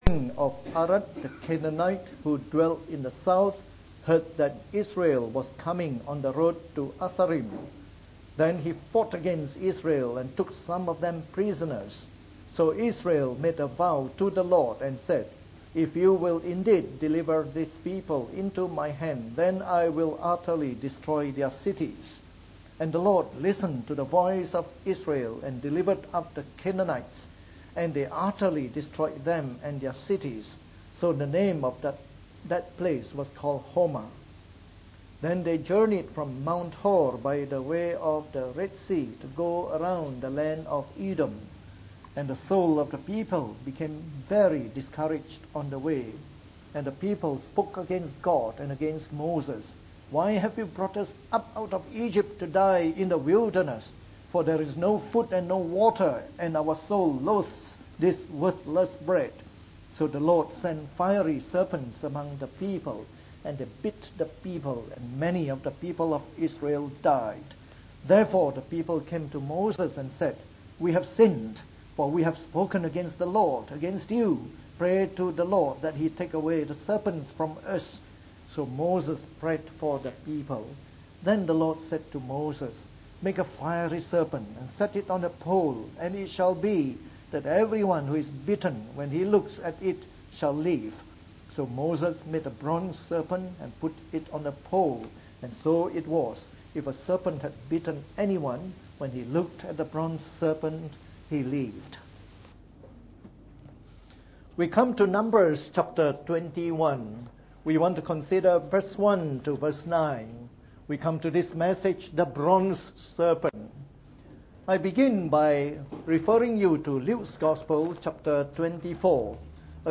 From our series on the “Book of Numbers” delivered in the Morning Service.